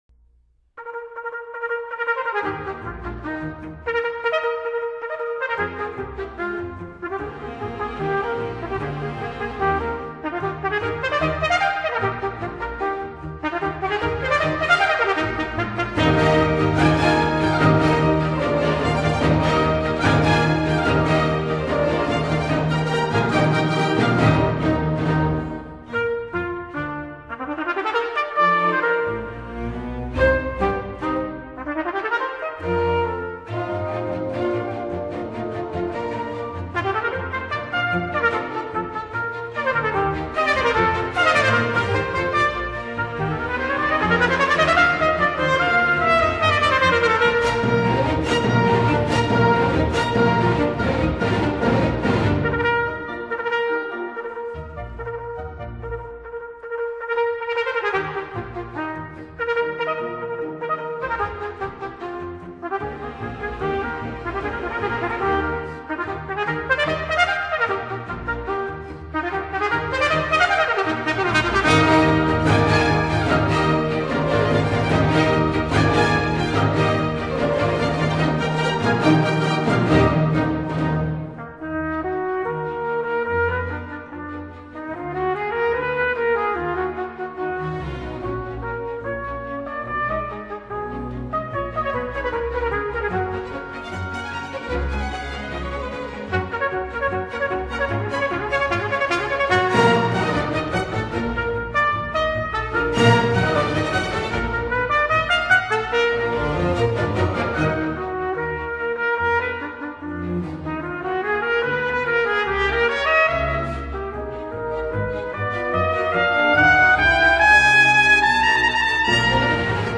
Classical
Concerto in E flat major for Trumpet and Orchestra (1806)